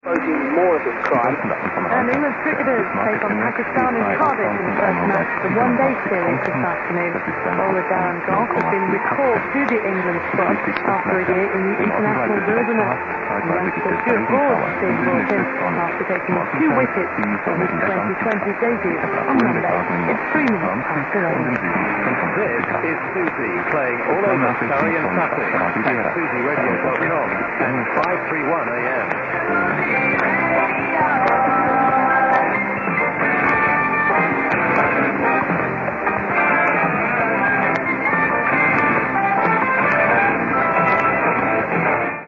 Susy 531 was detectable during the daytime in Belgium and the Netherlands